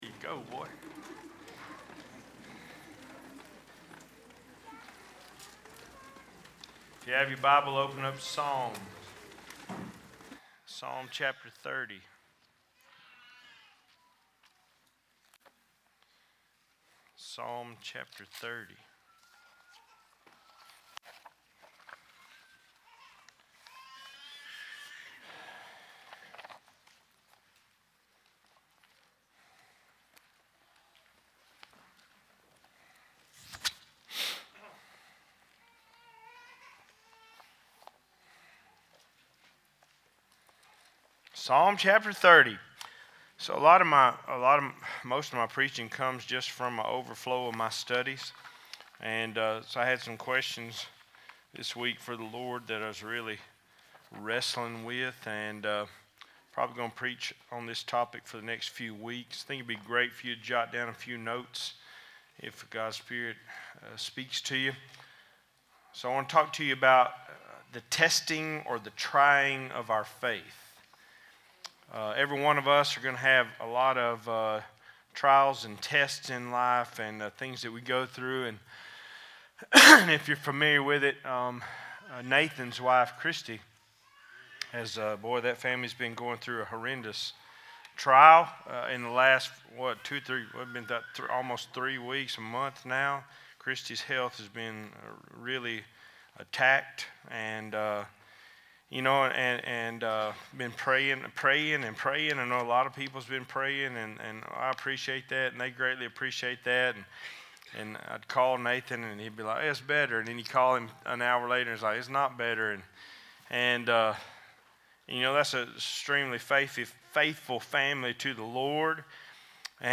7-9-23 Cedar Creek Missionary Baptist Church Sermons podcast